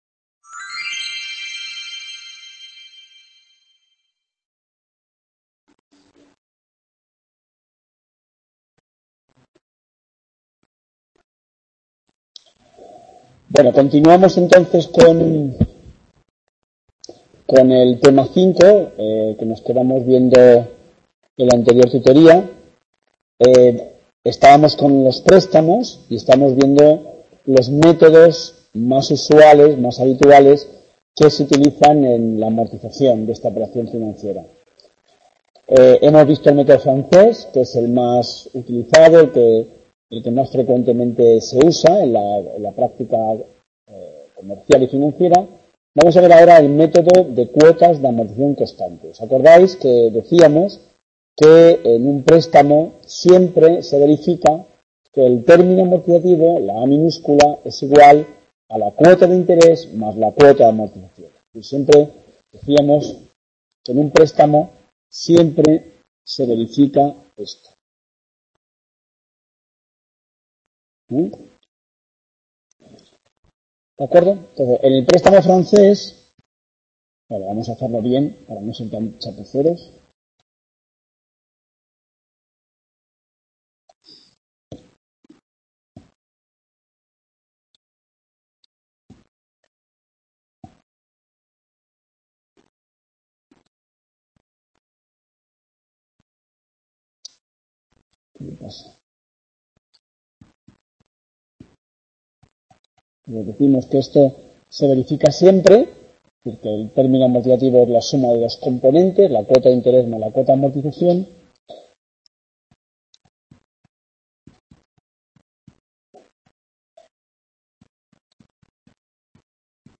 Tutoria GF Las Tablas 13 Mar 2019 Bis | Repositorio Digital